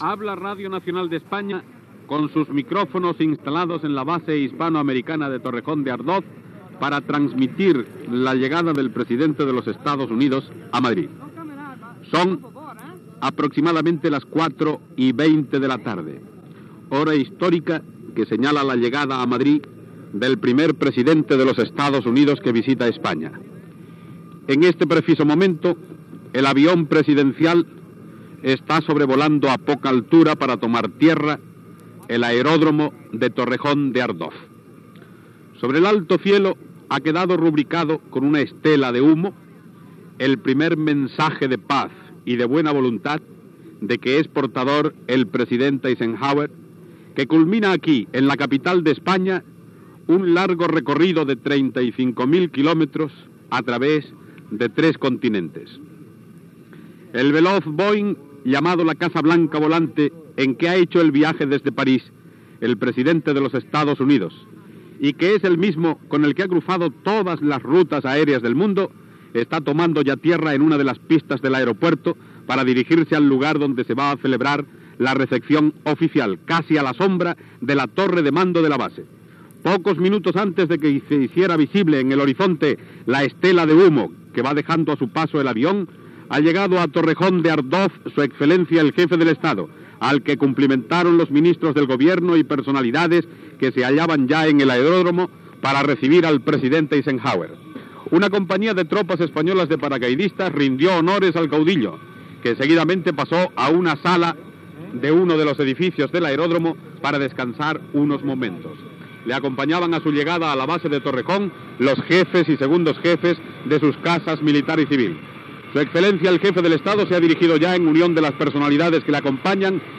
Transmissió, des de l'aeròdrom de Torrejón de Ardoz (Madrid), de l'arribada del president dels EE.UU. Dwight David Eisenhower on l'espera el cap d'estat el "generalísimo" Francisco Franco
Informatiu